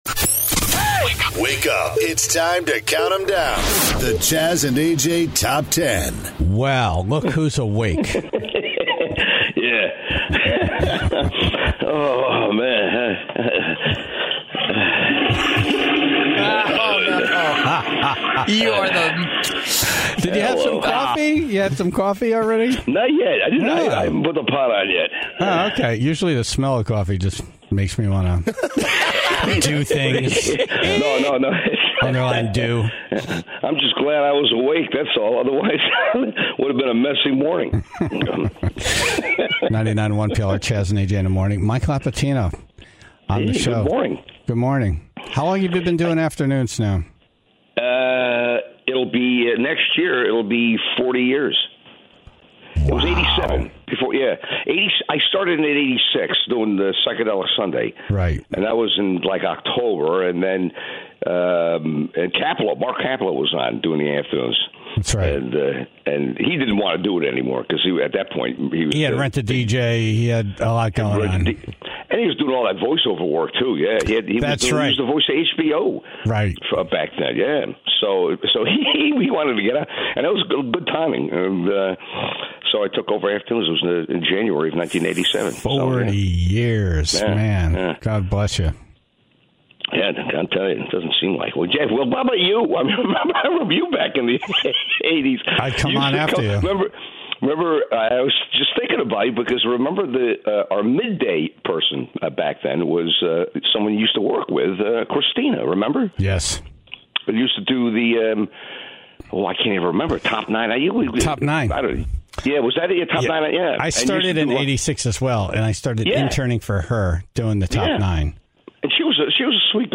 (19:03) The Tribe was calling in with their encounters with "nutbags," including a man repeatedly trying to order his food from the trash can in the drive thru.